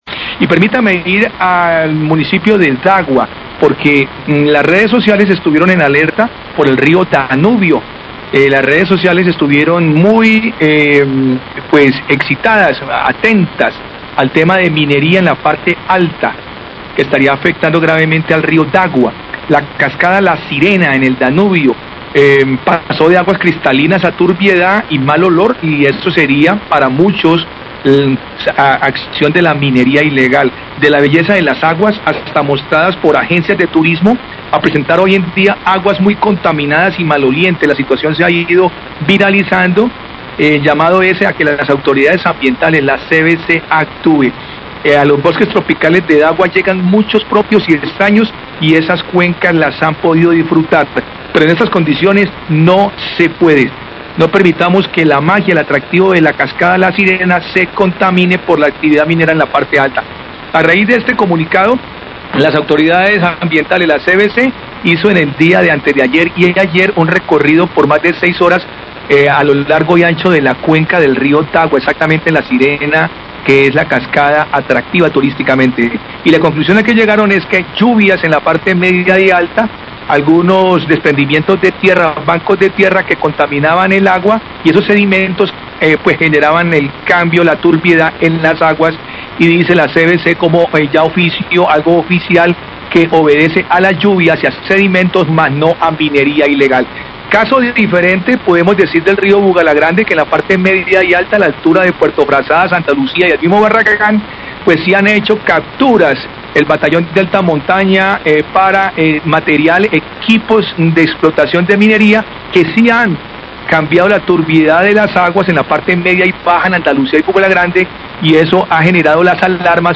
Radio
El corresponsal se refiere a lo que pasa en el río Bugalagrande donde el ejército nacional ha decomisado equipos de minería y, que empresas multinacionales explotan recursos afectando las aguas de este río.